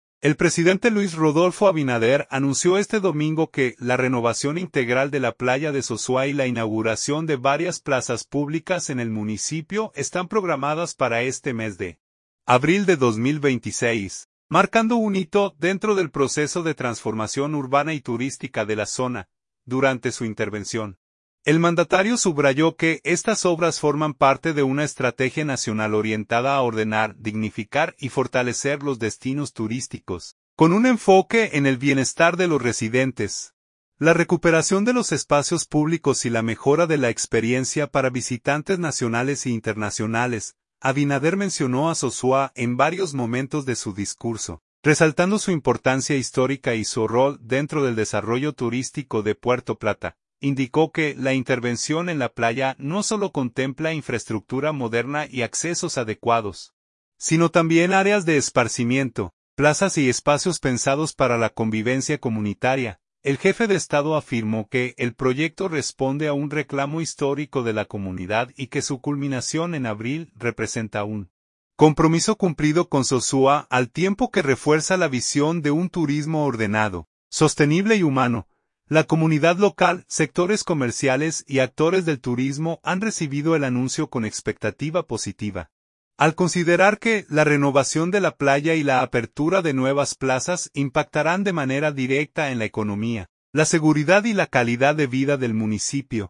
Abinader mencionó a Sosúa en varios momentos de su discurso, resaltando su importancia histórica y su rol dentro del desarrollo turístico de Puerto Plata.